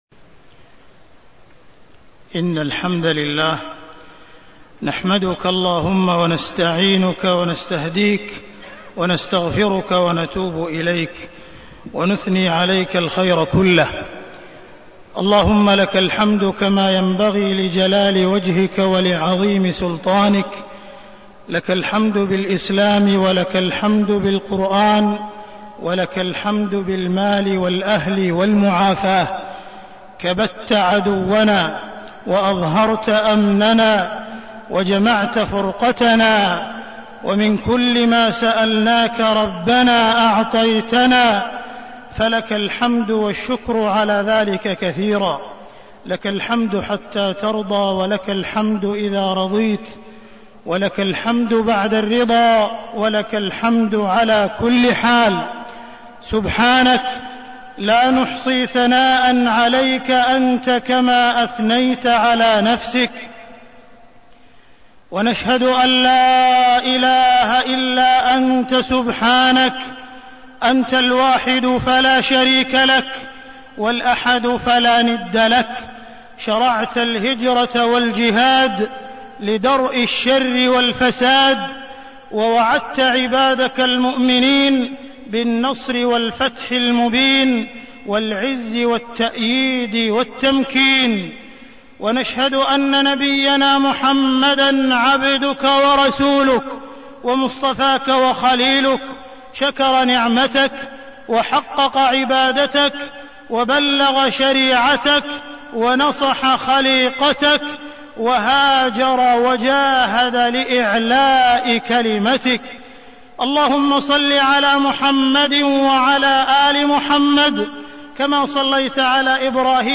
تاريخ النشر ٥ محرم ١٤١٩ هـ المكان: المسجد الحرام الشيخ: معالي الشيخ أ.د. عبدالرحمن بن عبدالعزيز السديس معالي الشيخ أ.د. عبدالرحمن بن عبدالعزيز السديس وقفات مع العام الهجري الجديد